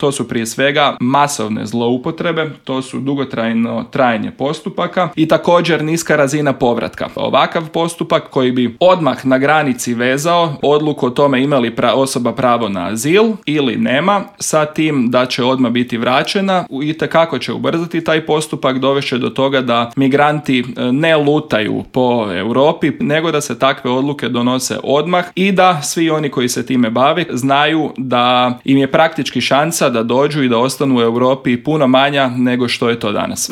Više detalja o Paktu, kako će se odraziti na Hrvatsku, ali i o kritikama na postignuti dogovor u intervjuu Media servisa razgovarali smo s eurozastupnikom iz redova HDZ-a, odnosno EPP-a Karlom Resslerom.